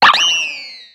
Cri de Shaofouine dans Pokémon X et Y.